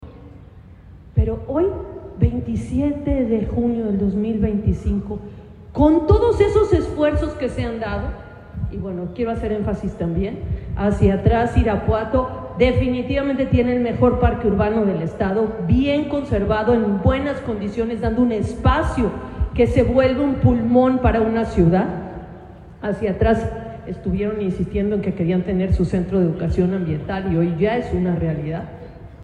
AudioBoletines
Isabel Ortiz Mantilla, diputada local